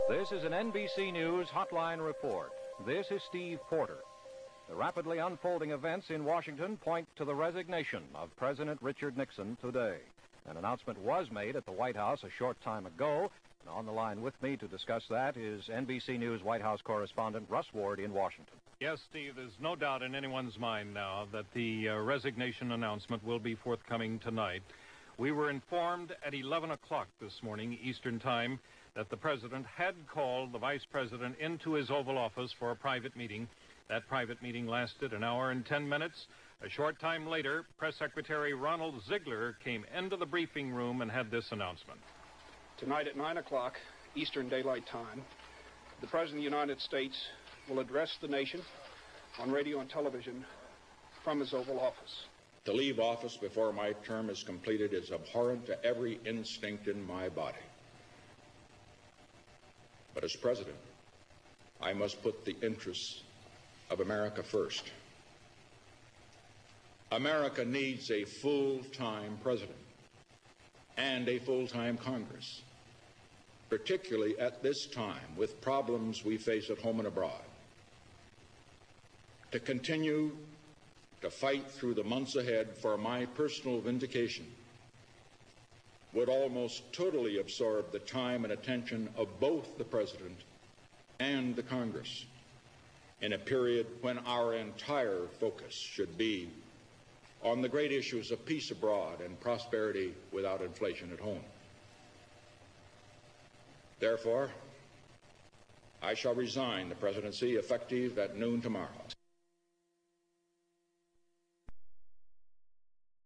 Nixon's resignation and his resignation speach.